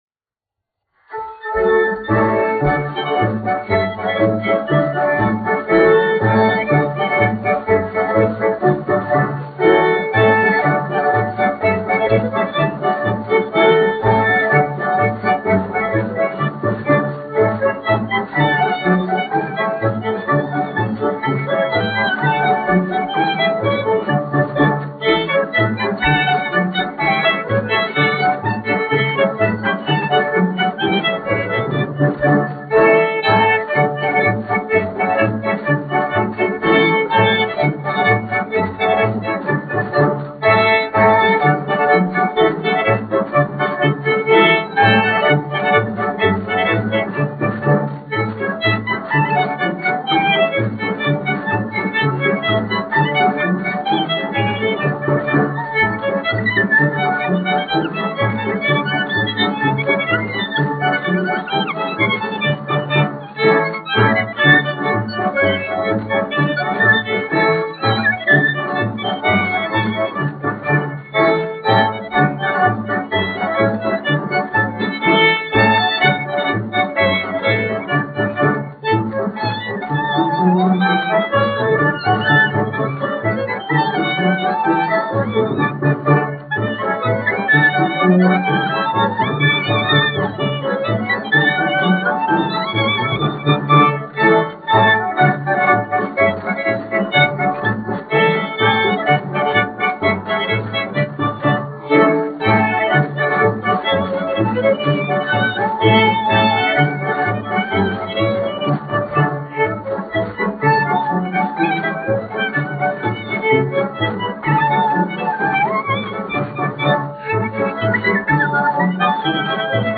Jautrā vija : tautas deja
1 skpl. : analogs, 78 apgr/min, mono ; 25 cm
Latviešu tautas dejas
Skaņuplate